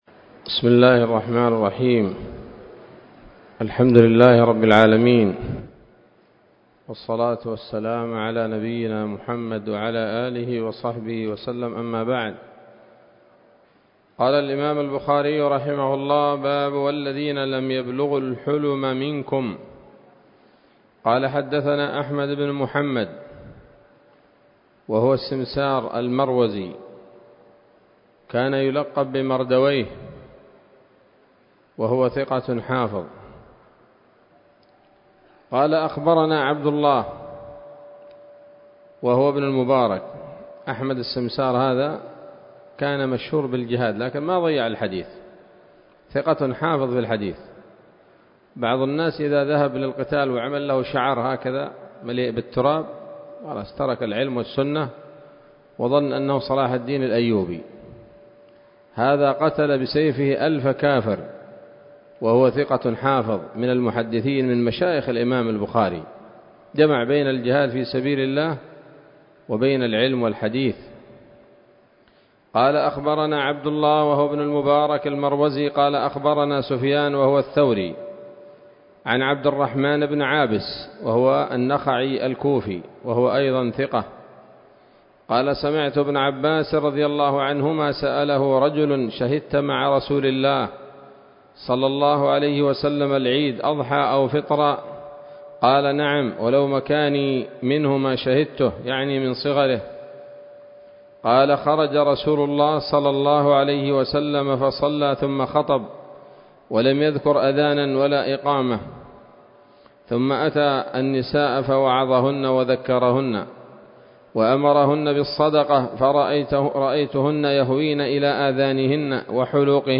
الدرس السادس والتسعون وهو الأخير من كتاب النكاح من صحيح الإمام البخاري